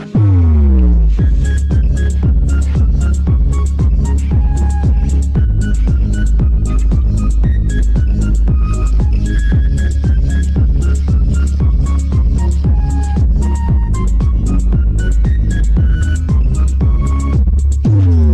Instrumentaaliset soittoäänet